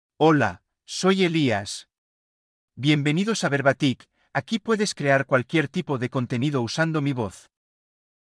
Elias — Male Spanish (Spain) AI Voice | TTS, Voice Cloning & Video | Verbatik AI
MaleSpanish (Spain)
Elias is a male AI voice for Spanish (Spain).
Voice sample
Listen to Elias's male Spanish voice.
Elias delivers clear pronunciation with authentic Spain Spanish intonation, making your content sound professionally produced.